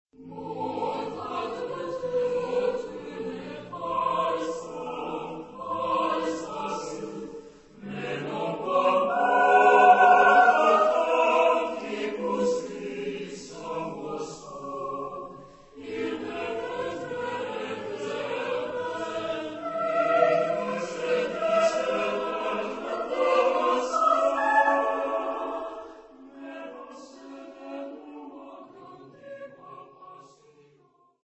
SATB (4 voix mixtes).
Type de choeur : SATB (4 voix mixtes )